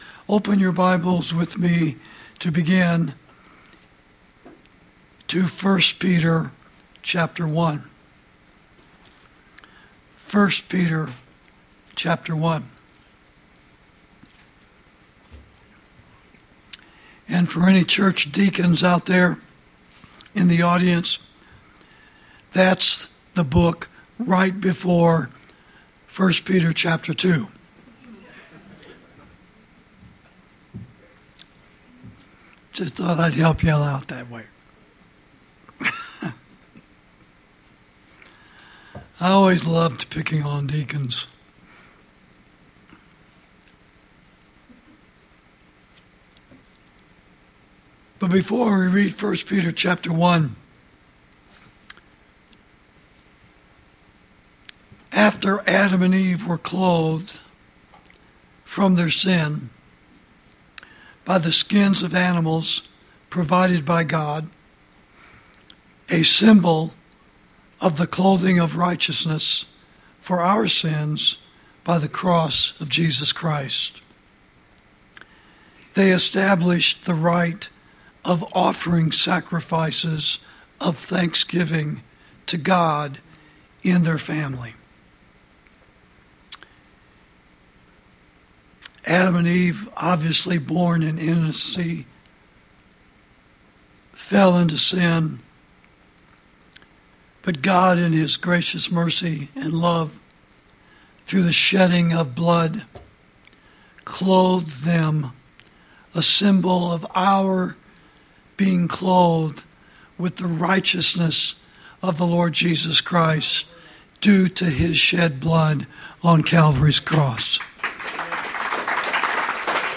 Sermons > How Dare America Presume On God's Protection!